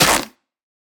Minecraft Version Minecraft Version 1.21.5 Latest Release | Latest Snapshot 1.21.5 / assets / minecraft / sounds / block / mangrove_roots / break1.ogg Compare With Compare With Latest Release | Latest Snapshot